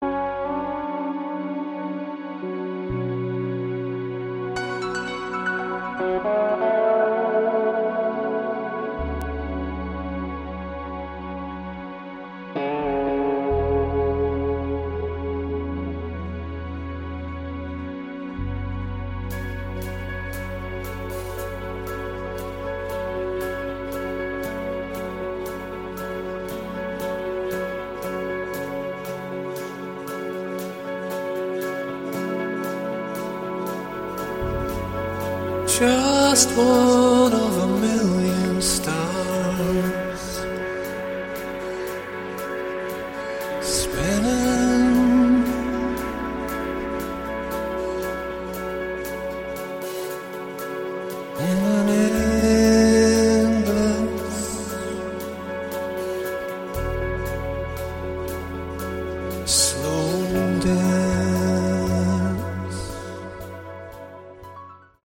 Category: Light/Westcoast AOR
vocals, guitars
bass
drums
keyboards